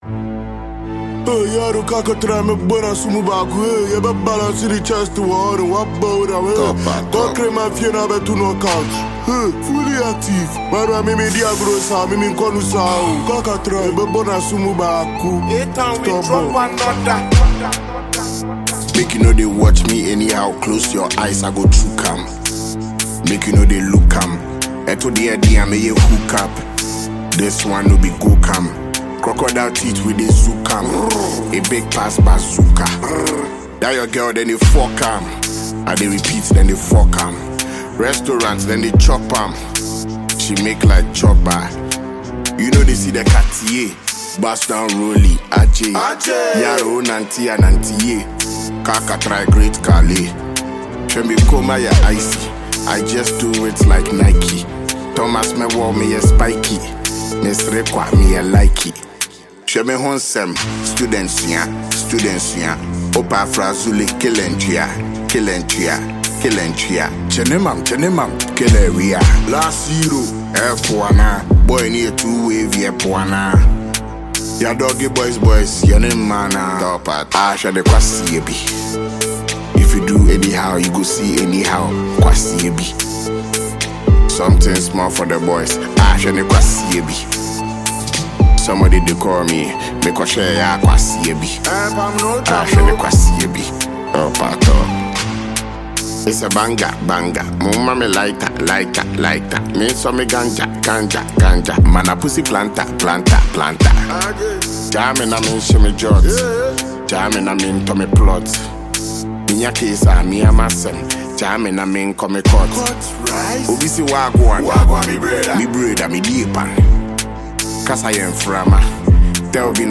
Heavyweight Ghanaian rapper
diss song